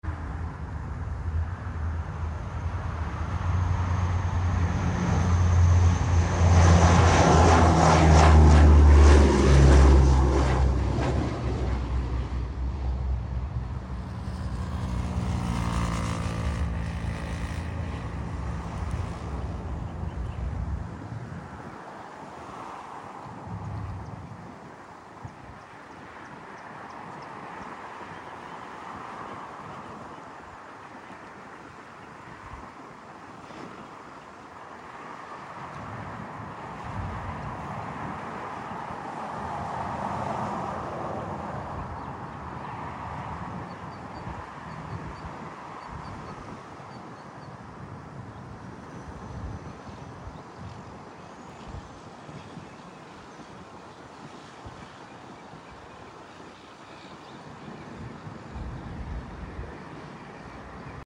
Antonov An12BK Ukraine Air Sound Effects Free Download